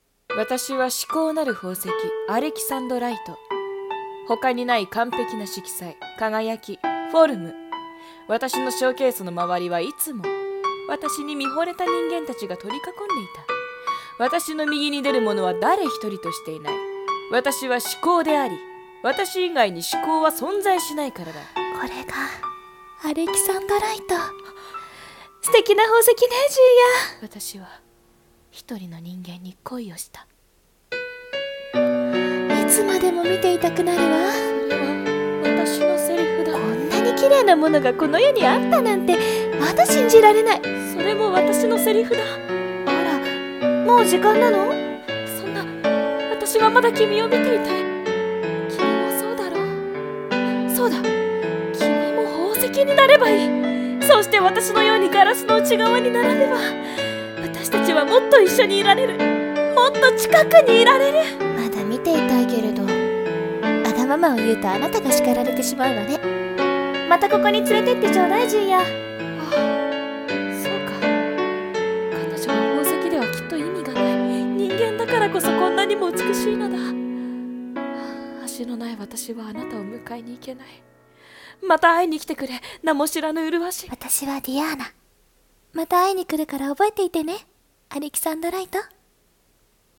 【声劇】至高なるアレキサンドライトの恋